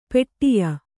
♪ peṭṭiya